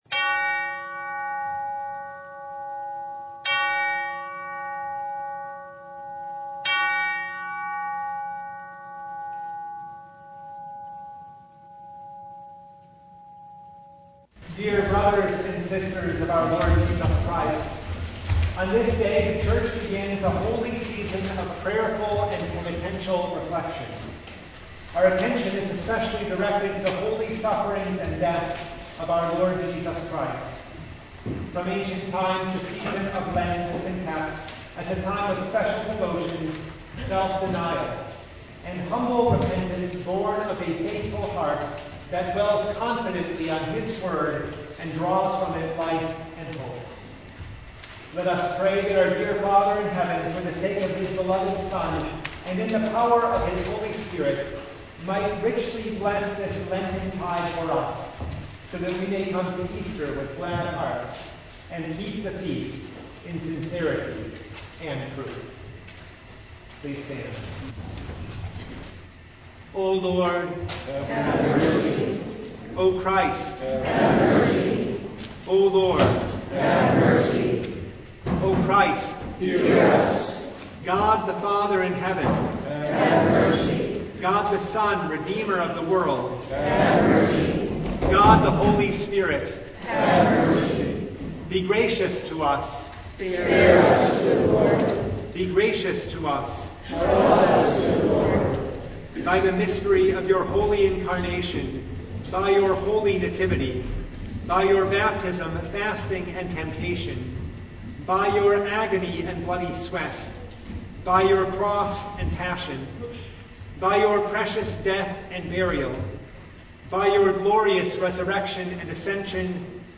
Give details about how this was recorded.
Ash Wednesday Noon Service (2023)